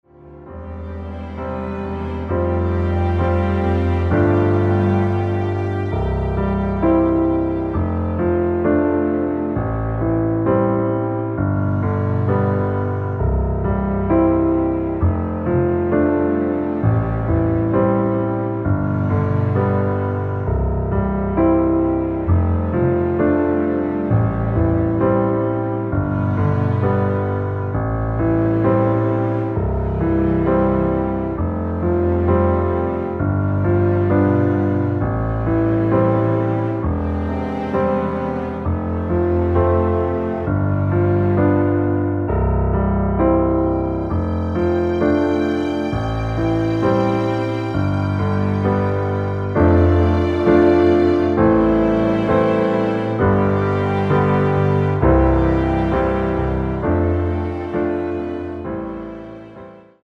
앞부분30초, 뒷부분30초씩 편집해서 올려 드리고 있습니다.
중간에 음이 끈어지고 다시 나오는 이유는
곡명 옆 (-1)은 반음 내림, (+1)은 반음 올림 입니다.